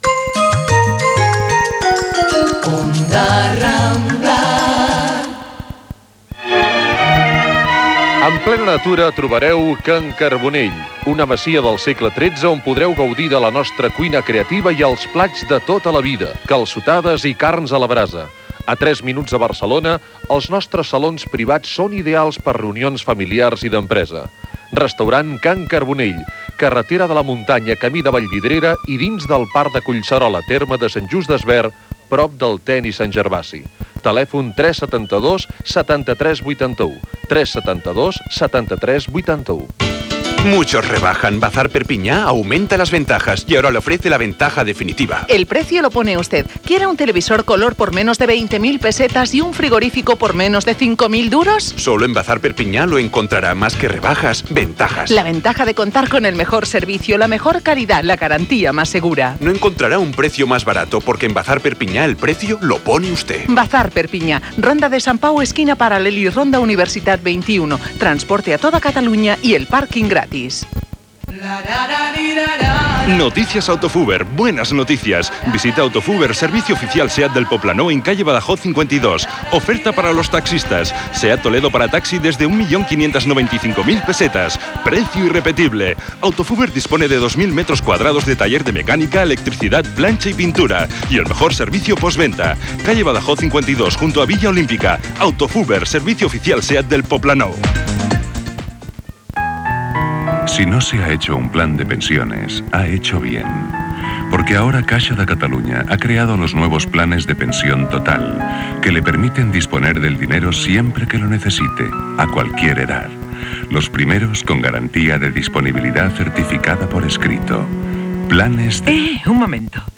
Indicatiu, publicitat, notícies: detinguts del GRAPO, OTAN, Copa de la UEFA i el trànsit.
Informatiu
FM